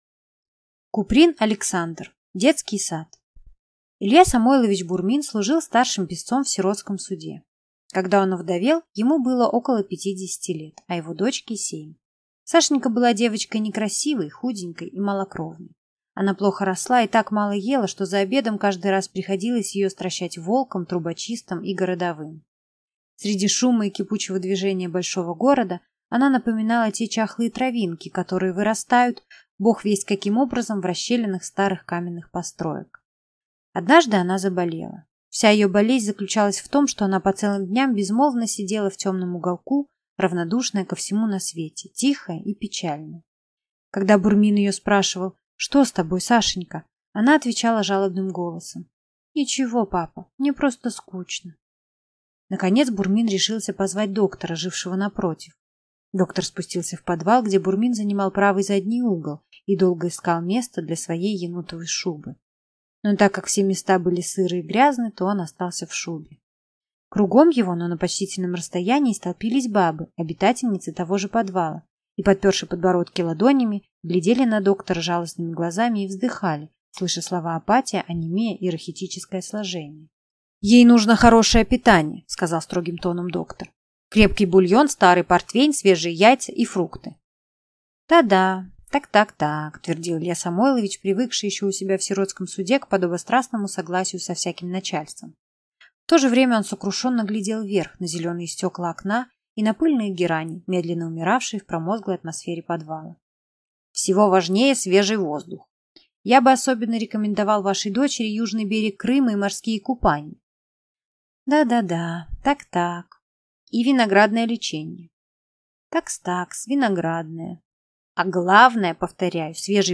Aудиокнига Детский сад